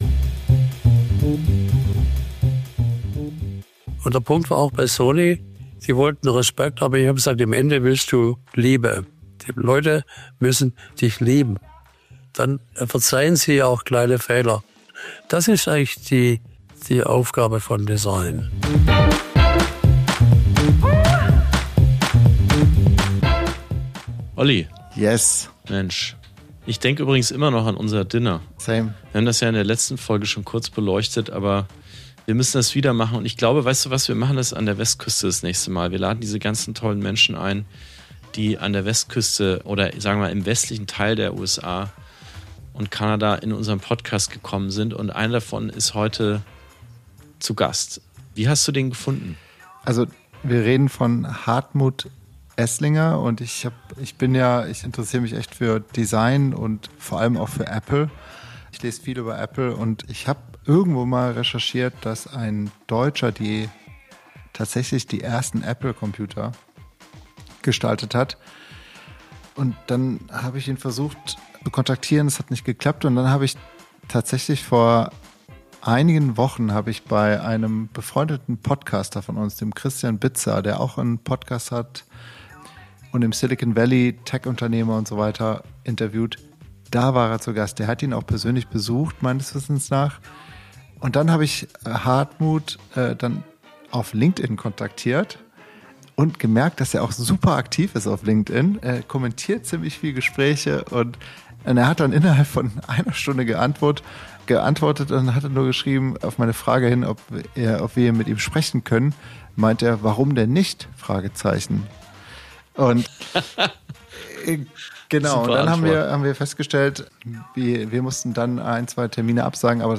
Liebe Wunderbar-Crew, wir haben lange auf dieses Gespräch hingearbeitet, und sind umso glücklicher, die Folge jetzt endlich releasen zu können. Hartmut Esslinger hat uns aus seinem Haus in Los Gatos, Kalifornien, in sein Leben und auf seine Reise mitgenommen – und was für eine Reise das ist!